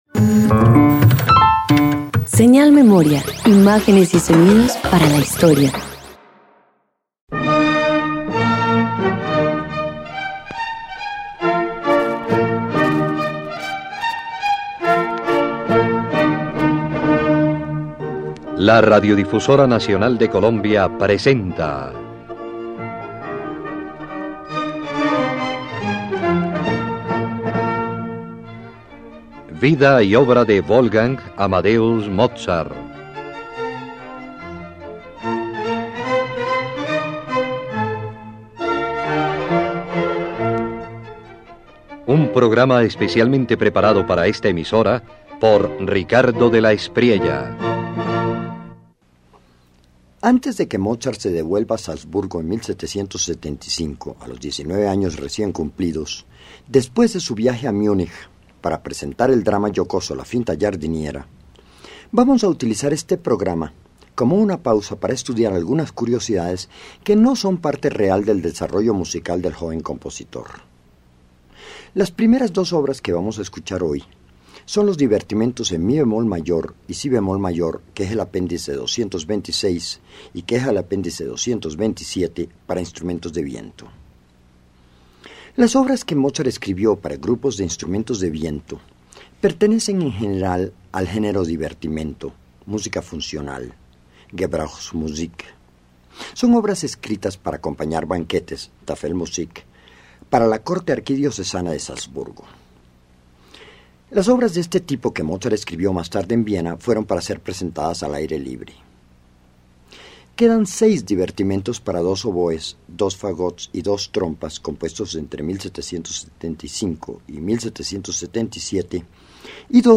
Antes de volver a Salzburgo, en 1775, Mozart compone varios divertimentos para instrumentos de viento: piezas ligeras, alegres y funcionales, cuya autenticidad aún se debate, pero que revelan el encanto y la elegancia de su estilo juvenil.